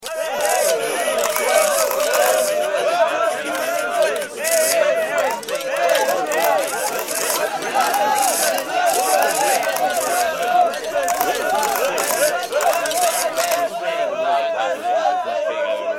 دانلود صدای دوربین 3 از ساعد نیوز با لینک مستقیم و کیفیت بالا
جلوه های صوتی